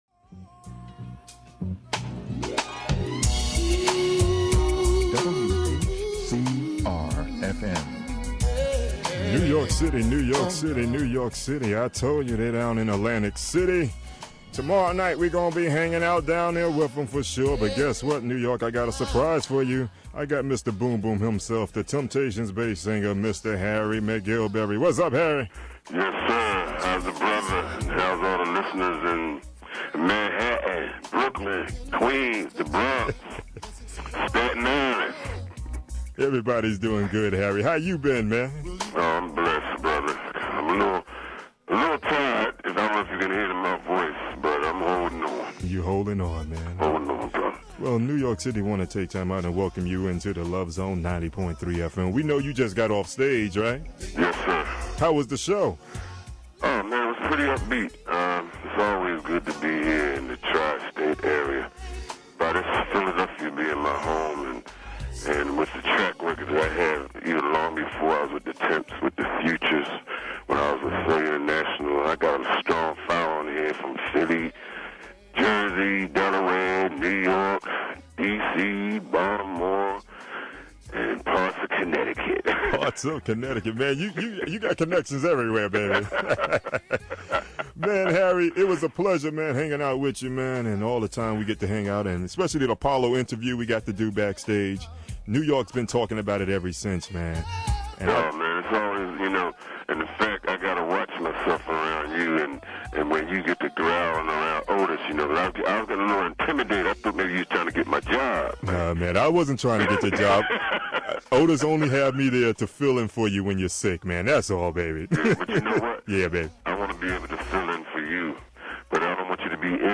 Harry McGillberry 06-20-03 Interview
Harry-McGillberry-Interview.mp3